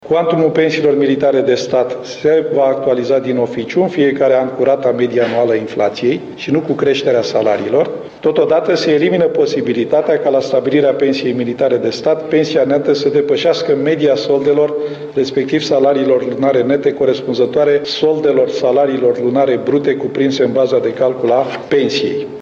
Ministrul Apărării, Adrian Ţuţuianu, a anunţat înaintea şedinţei de guvern principalele reglementări.